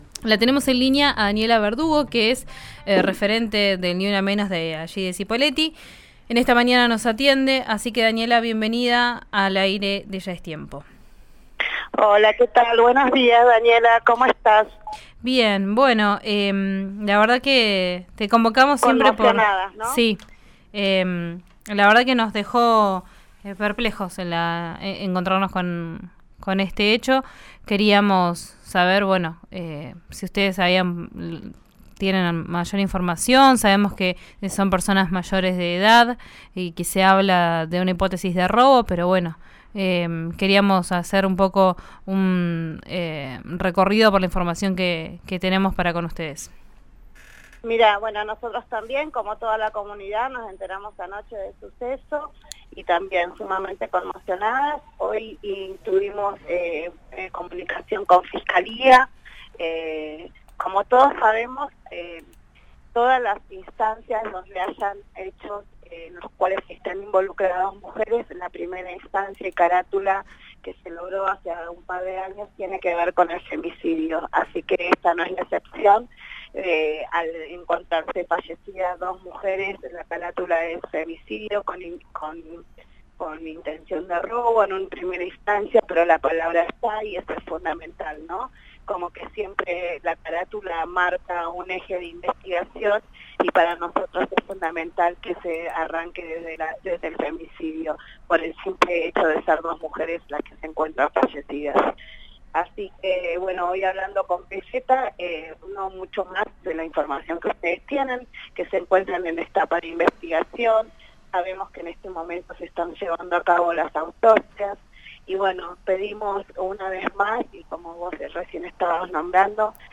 Doble femicidio en Cipolletti: «Pedimos a quienes puedan aportar algún dato que colaboren», dijeron desde Ni Una Menos